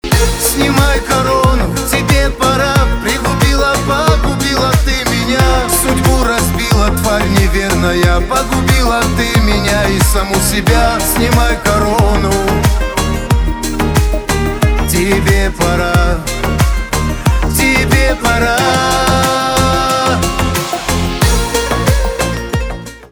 кавказские
гитара , битовые
грустные , печальные